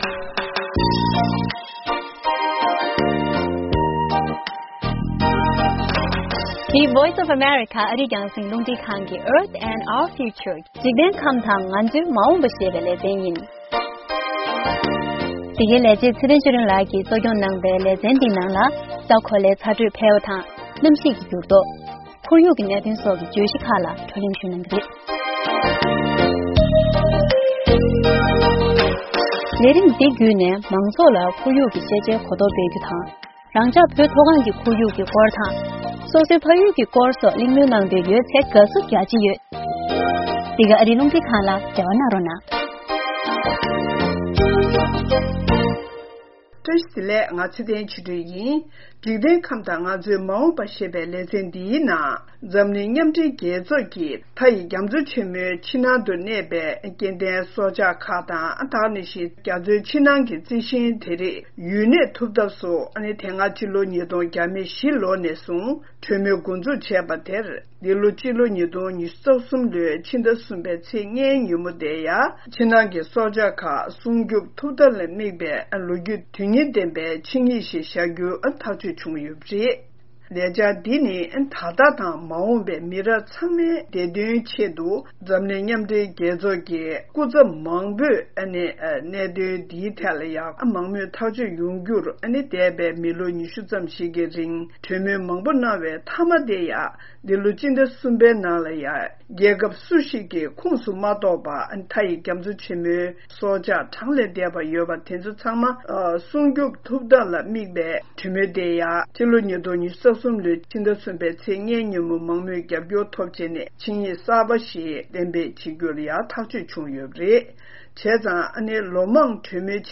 སྙན་སྒྲོན་ཞུ་ཡི་རེད།